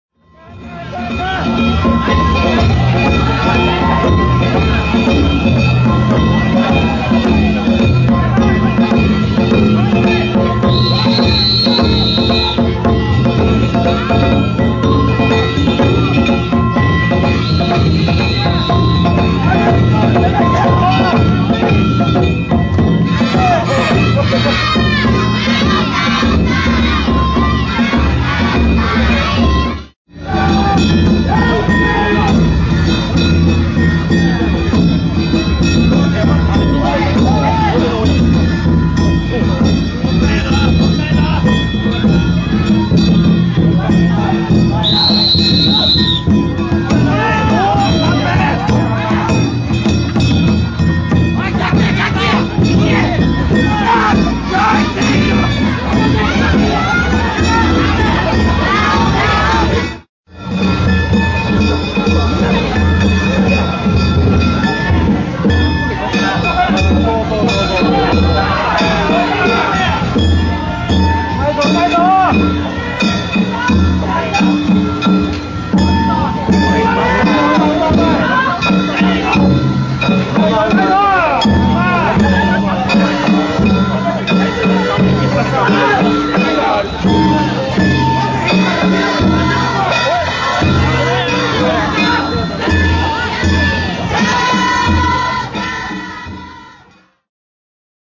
宵宮夜の太鼓地車パレードです。
重厚な太鼓の音を響かせながら進みます。